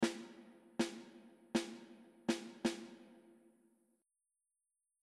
Once you get comfortable clapping these notes, try adding eighth notes and quarter notes together in groups of 4 beats.
♩♩♩= 1 2 3 4 and